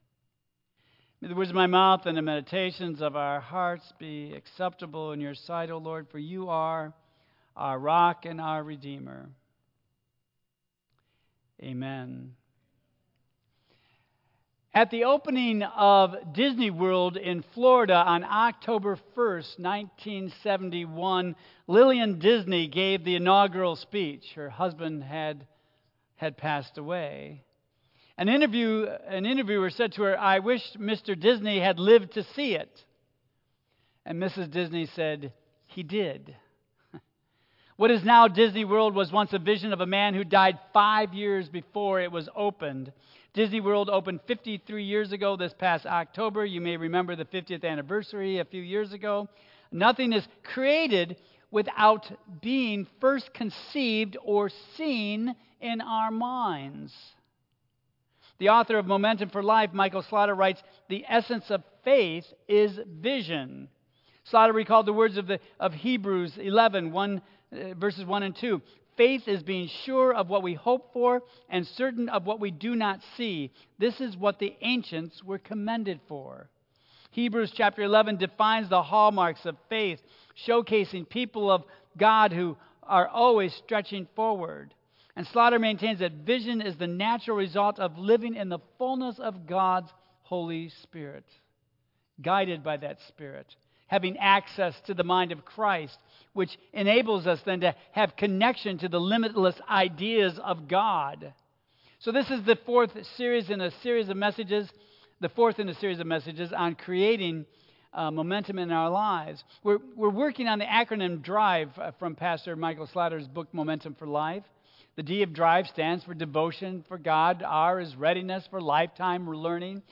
Tagged with Michigan , Sermon , Waterford Central United Methodist Church , Worship Audio (MP3) 7 MB Previous Investing in Real Relationships Next A Worthy Sacrifice